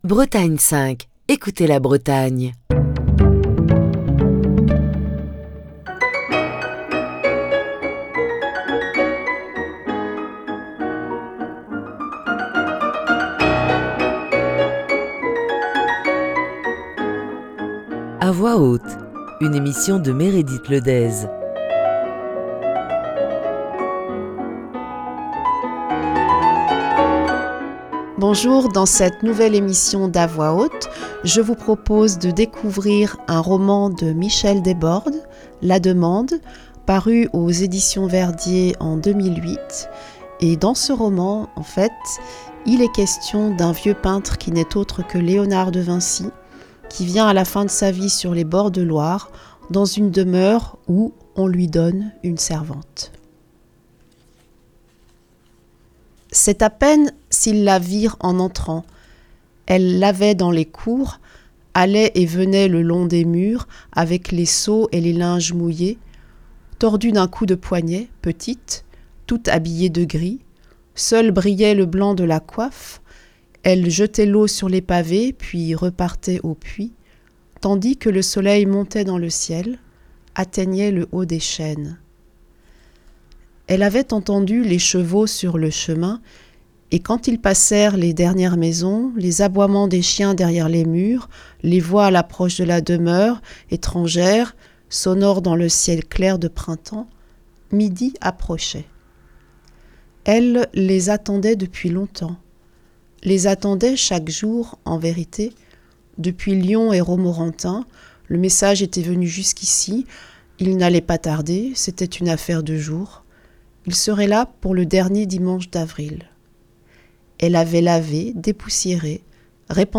Émission du 24 septembre 2022.